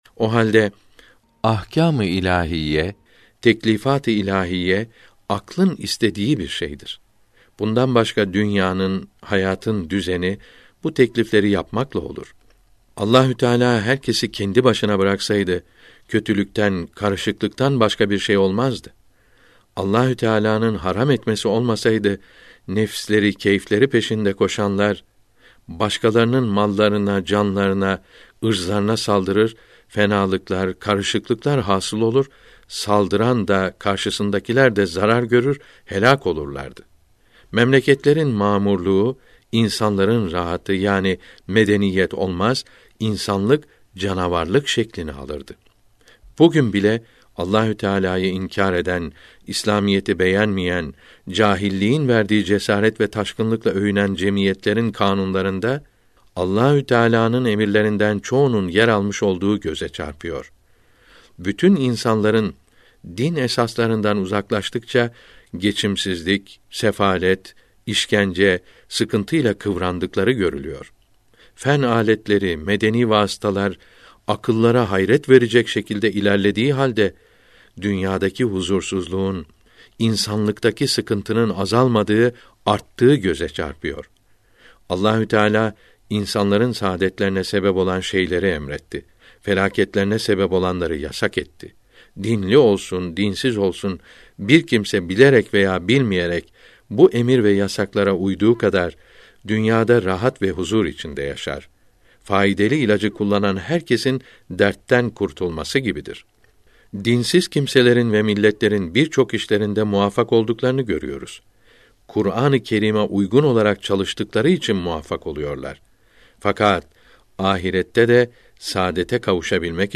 Mono